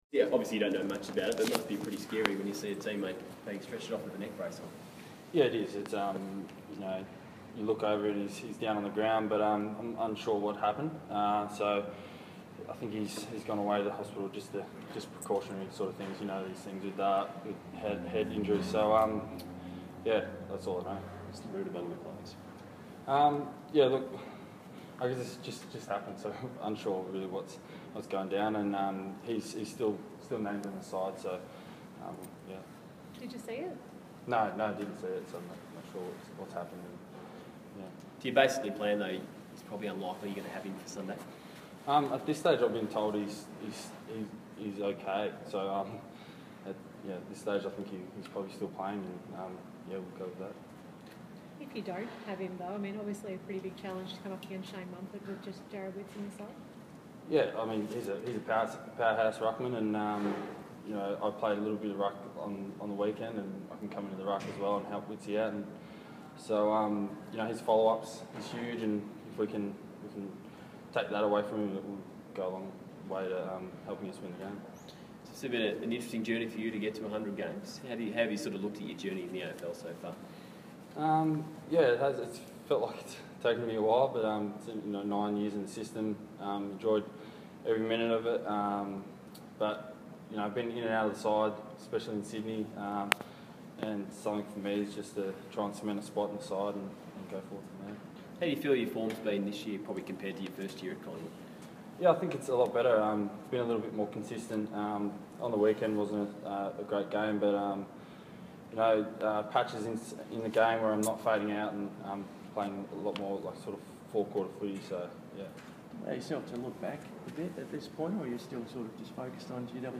Press Conference: Jesse White